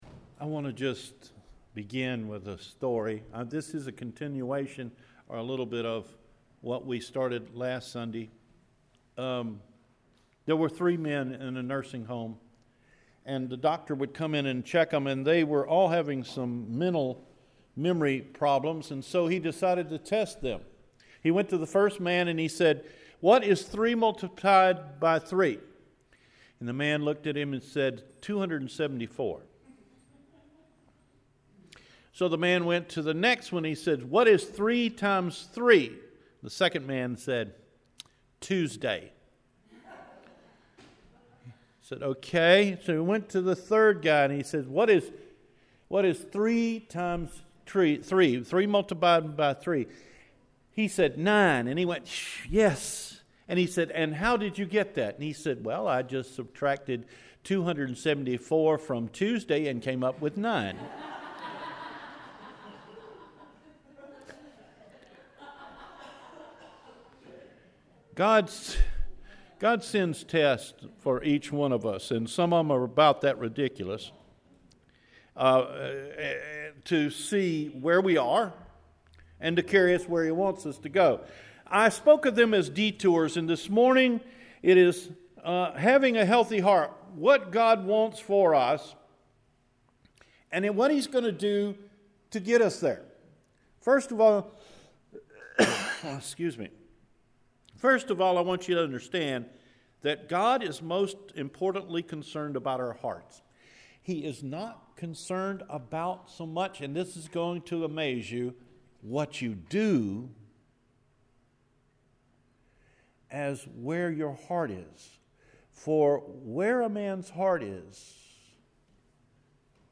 Having a Healthy Heart – June 4 Sermon